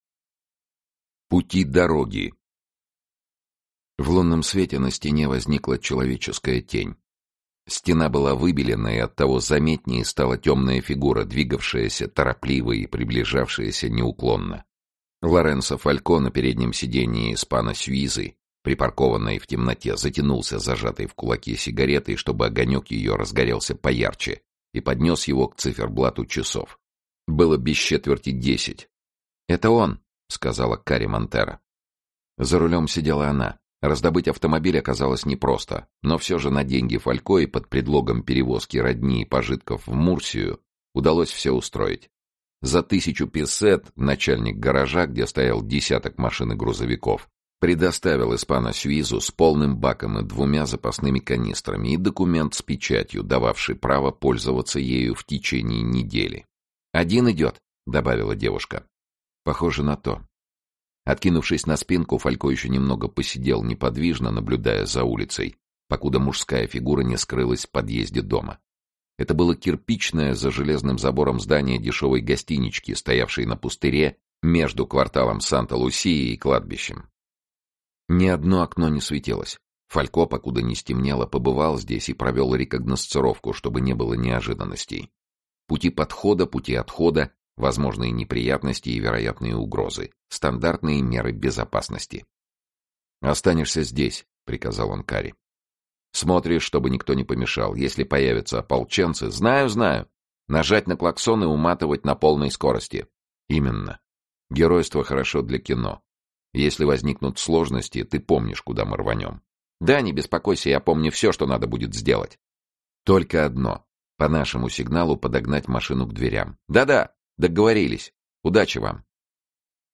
Кодек mp3 Битрейт 96 кбит/сек Частота дискретизации 44.1 КГц MD5 Фалько Аудиокнига